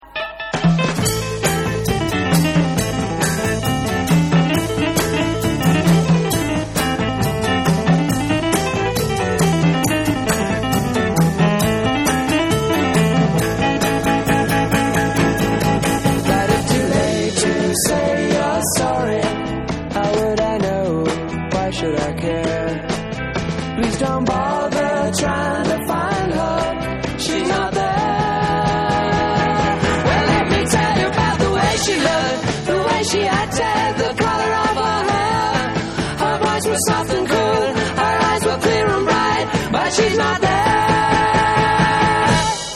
Audio Demo Wurlitzer 200 A